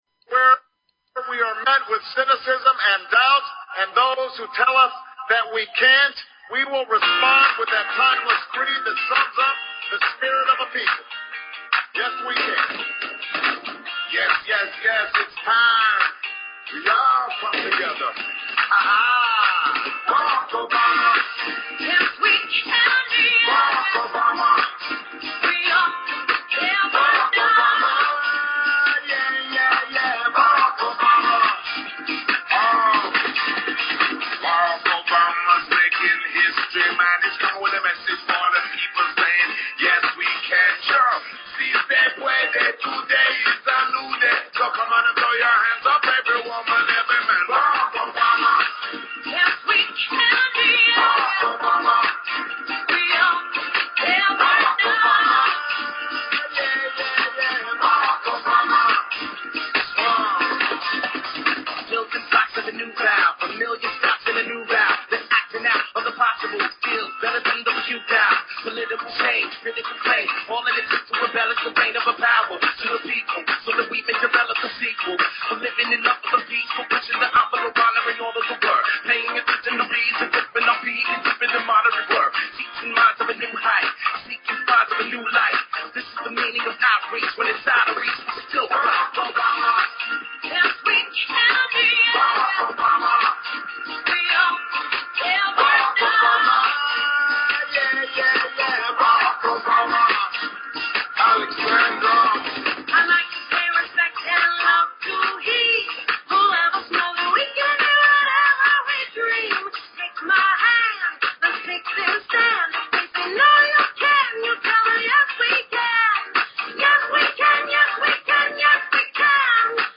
Talk Show Episode, Audio Podcast, The_Galactic_Round_Table and Courtesy of BBS Radio on , show guests , about , categorized as